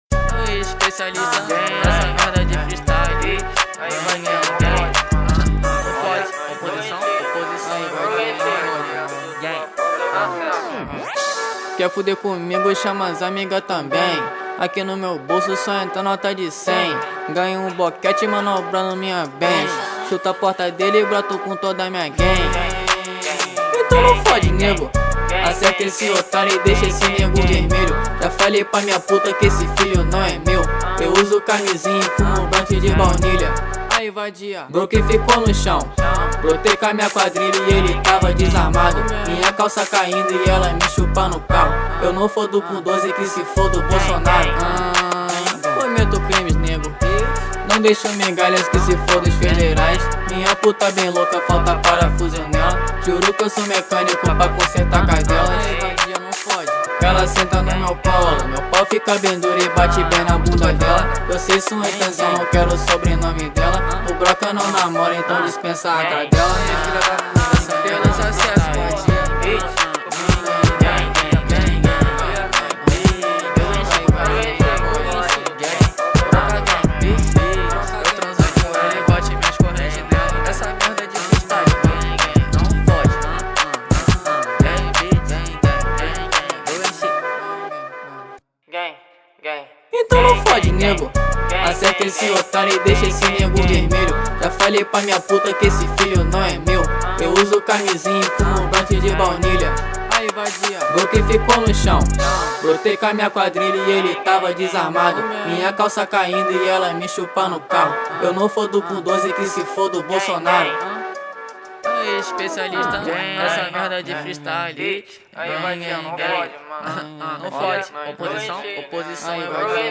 2024-05-06 17:32:17 Gênero: Trap Views